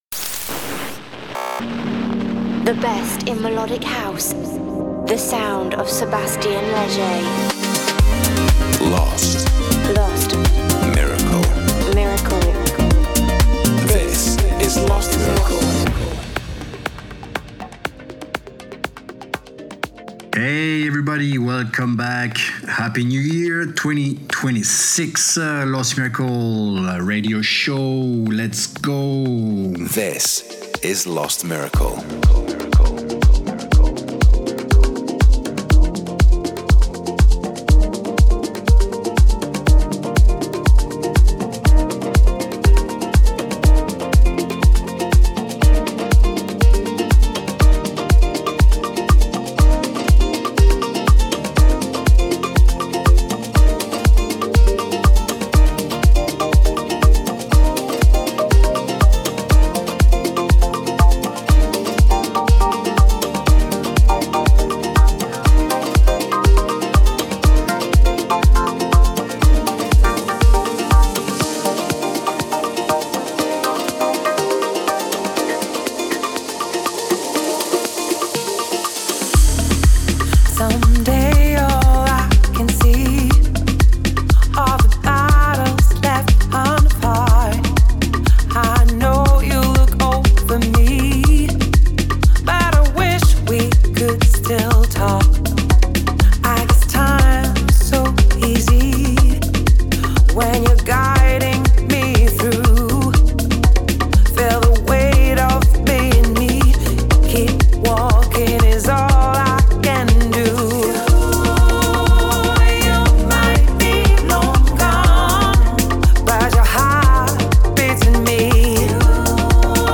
Also find other EDM Livesets, DJ Mixes and Radio Show
the monthly radio show